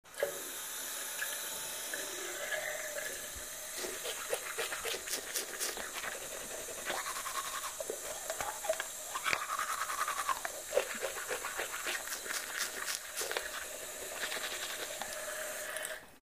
Звуки фена
На этой странице собраны различные звуки работающего фена: от тихого гула до мощного потока воздуха.